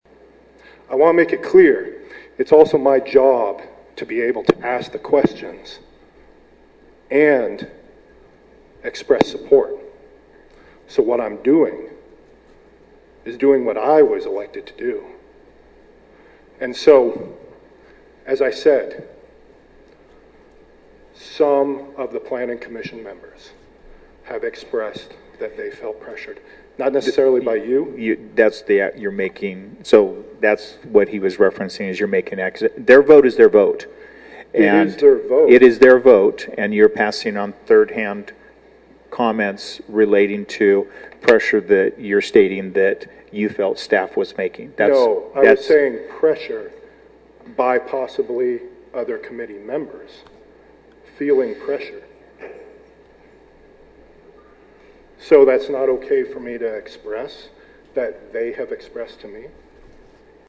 Council revisited the new rules during their meeting Tuesday night.
The discussion became heated when Pullman City Council’s senior most member, Nathan Weller, tried to bring up concerns that he heard directly from some planning commission members.
You can hear one of those interruptions from Mayor Benjamin below.